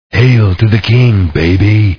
hail.wav